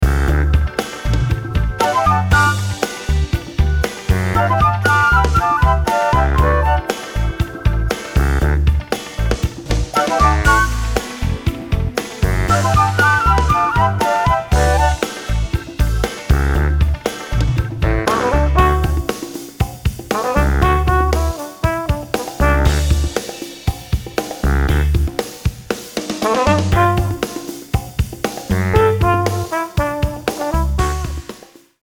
118 BPM